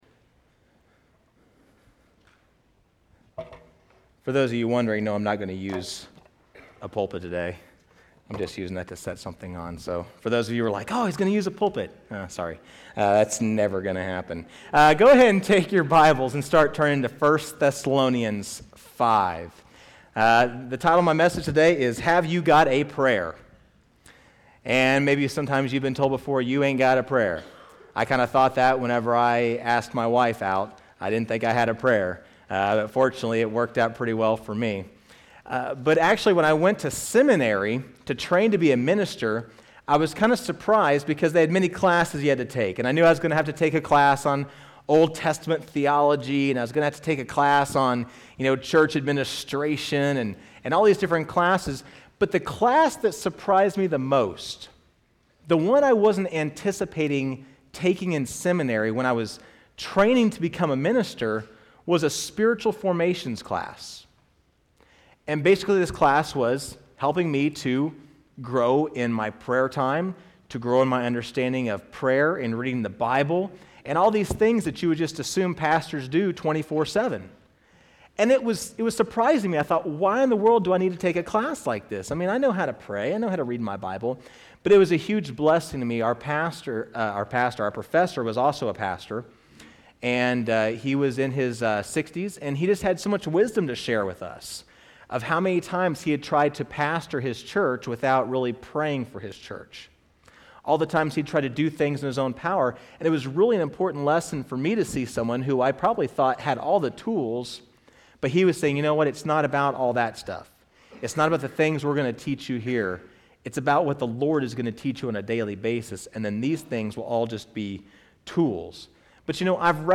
First Baptist Church of Machesney Park Sermon Audio